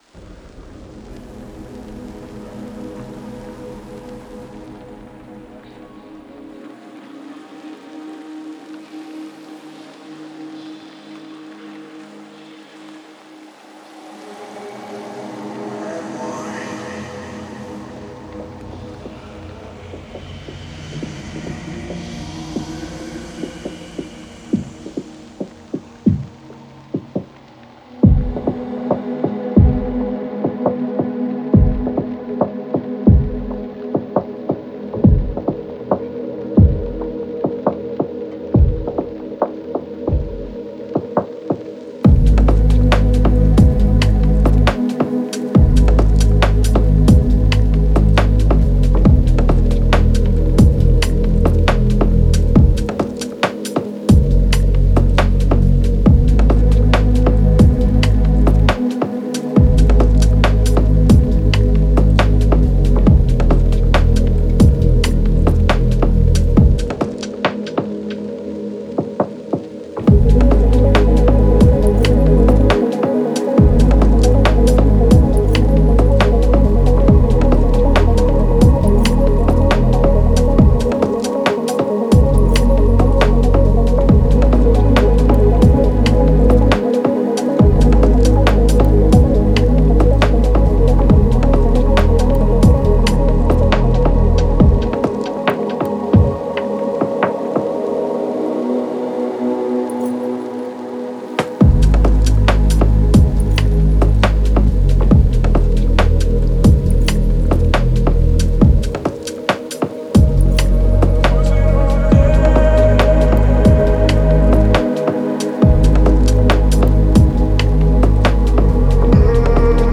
это захватывающая композиция в жанре даунтемпо и электроники
Благодаря своему меланхоличному настроению